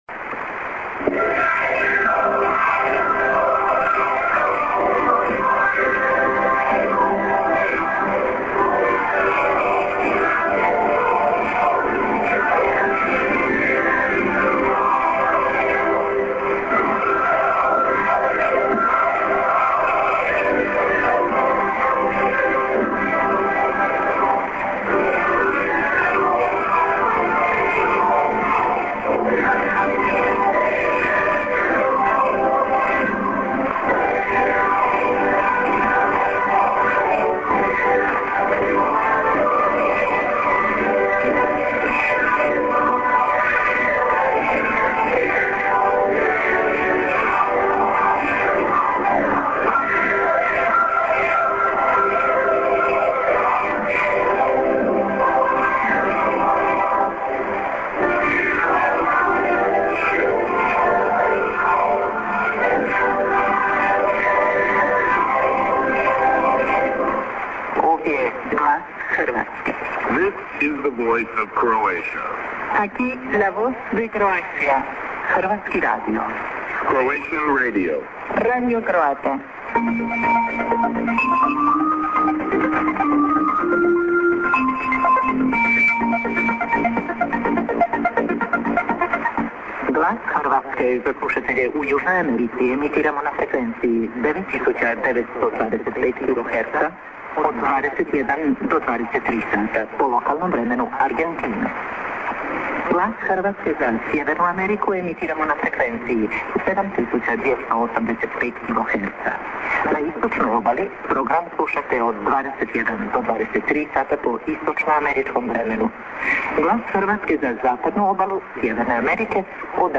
St. music->ID+SKJ(women+man:Malt.Lang)->　V.O. Croatia
混信もなく良好です。複数の言語でＩＤとＳＫＪをアナウンスしています。